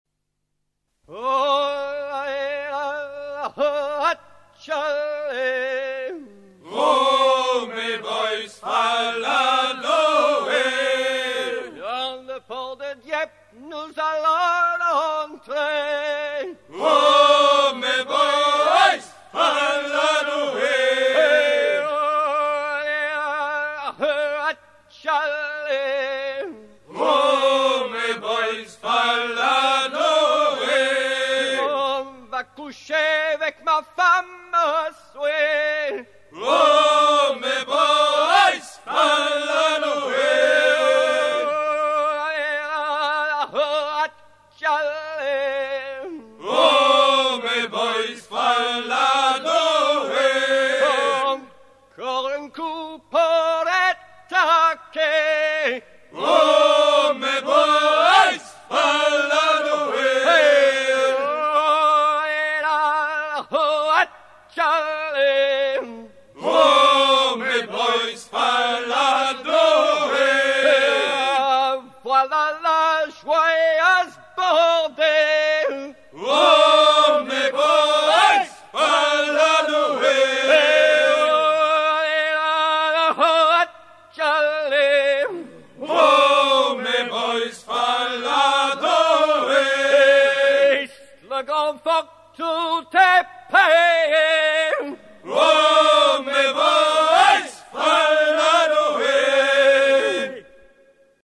Note chant noté à Dieppe à la fin du XXe siècle
Thème : 1126 - chants brefs - Carillon
Genre brève